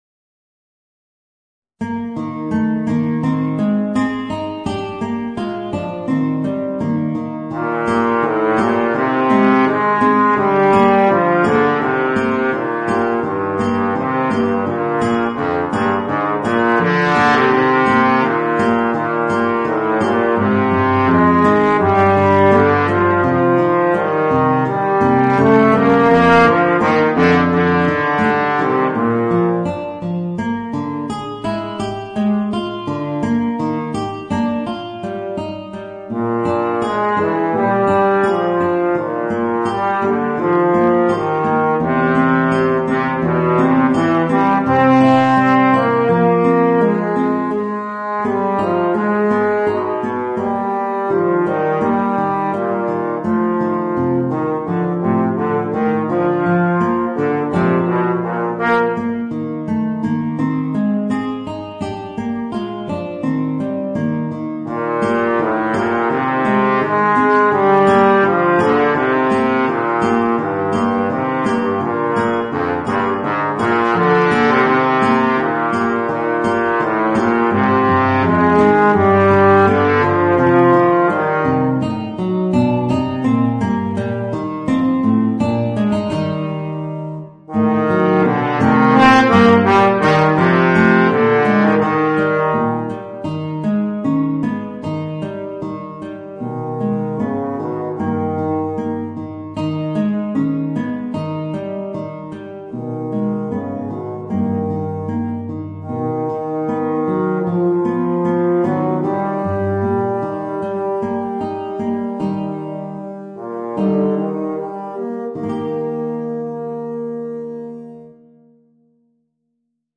Voicing: Bass Trombone and Guitar